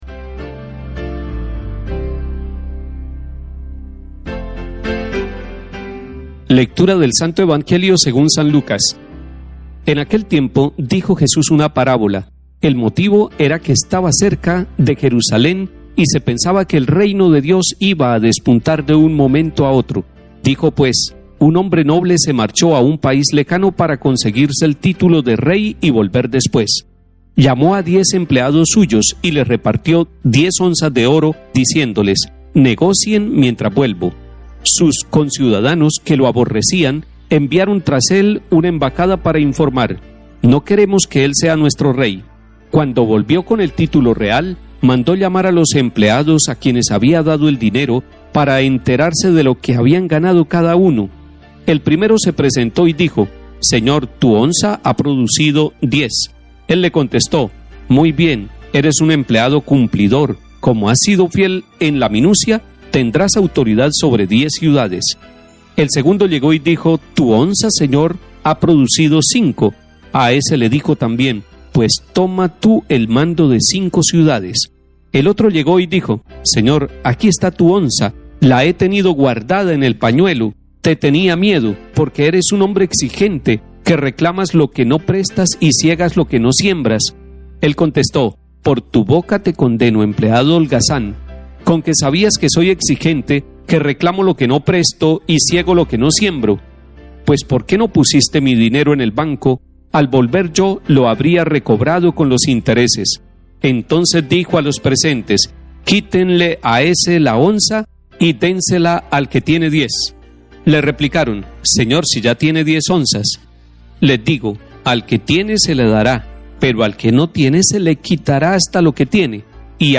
Comentario del Cardenal Mons. Luis José Rueda Aparicio, Arzobispo de Bogotá y Primado de Colombia.